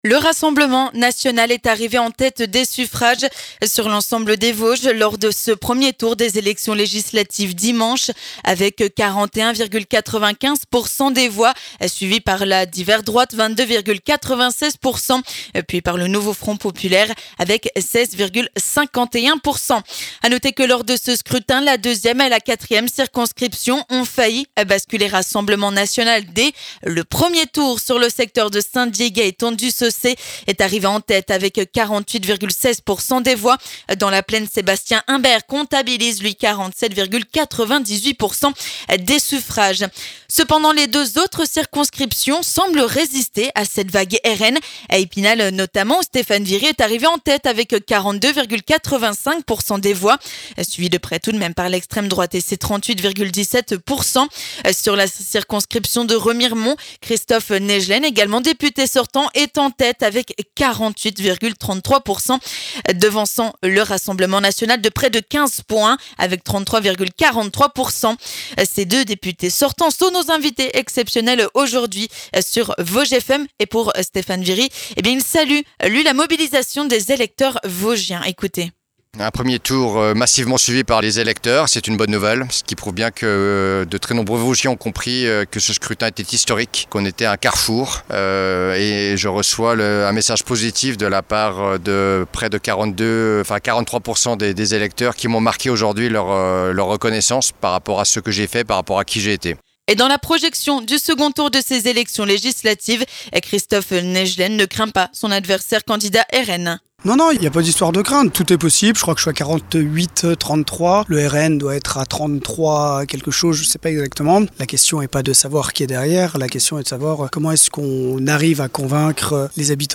Stéphane Viry, député sortant sur la 1ère circonscription, et Christophe Naegelen, député sortant pour la 3ème circonscription, réagissent au micro de Vosges FM aux résultats du premier tour des élections législatives anticiépes dans les Vosges.